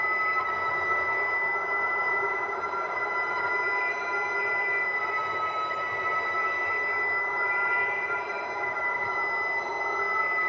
bomb_whistle_loop.wav